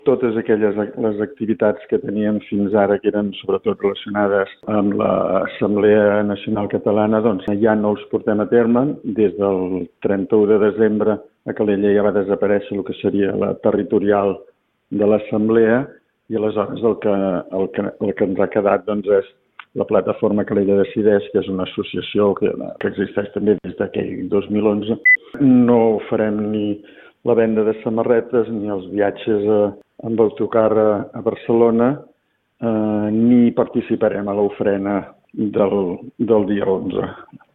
en una entrevista a RCT.